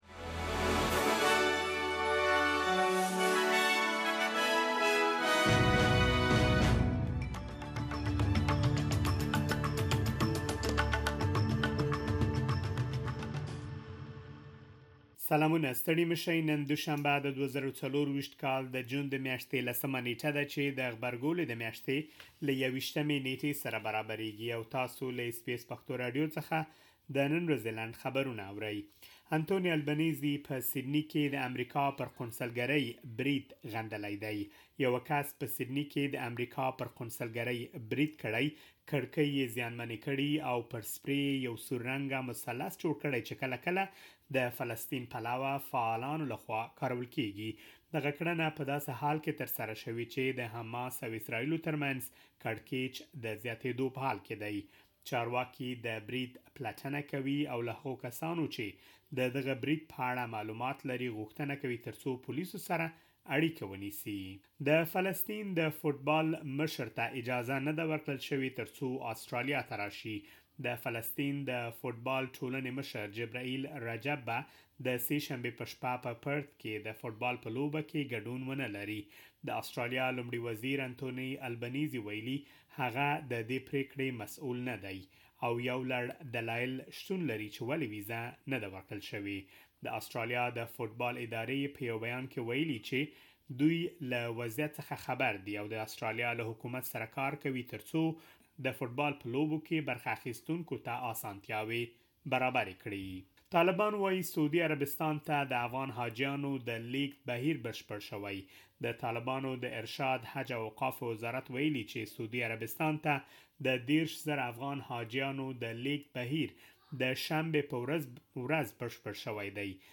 د اس بي اس پښتو د نن ورځې لنډ خبرونه|۱۰ جون ۲۰۲۴
د اس بي اس پښتو د نن ورځې لنډ خبرونه دلته واورئ.